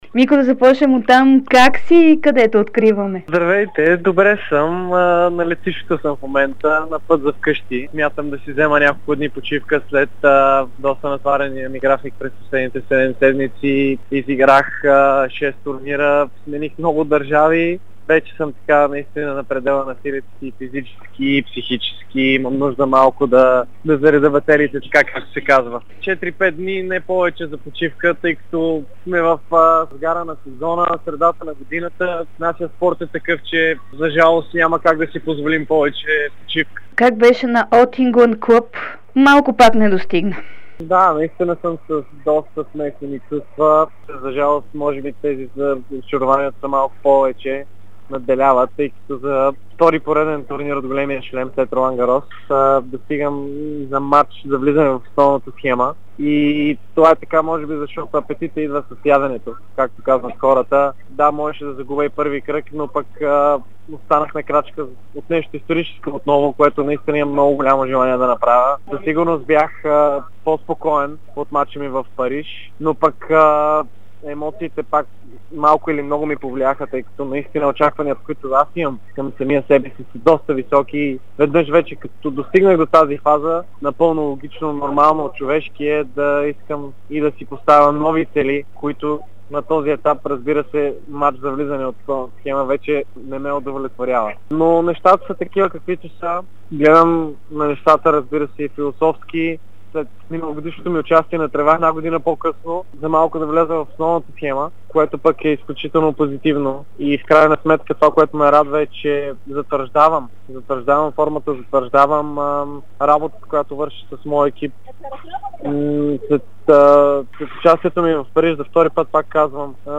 Втората ракета на България Димитър Кузманов даде специално интервю за dsport и Дарик радио. Той говори за емоциите след опита на Уимбълдън и спечелената купа в Ливърпул.